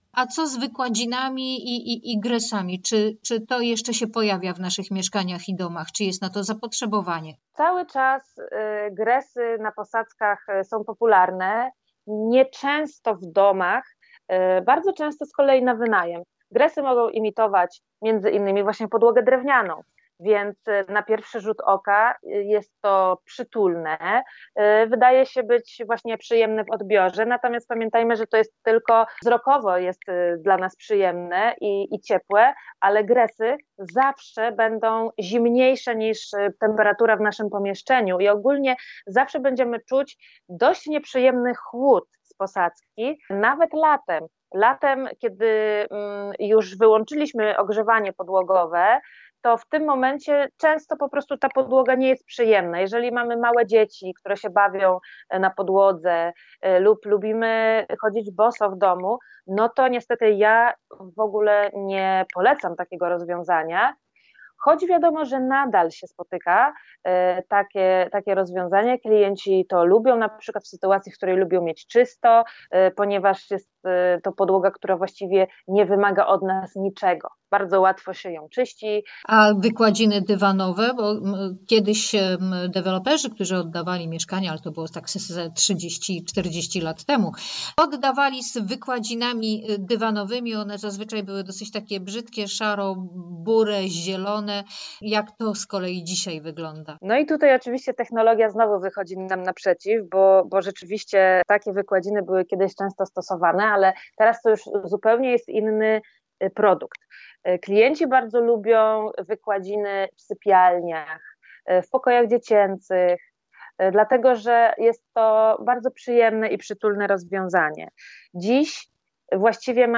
Flickr) /audio/dok3/dm160620.mp3 Tagi: archiwum audycji Dobrze mieszkaj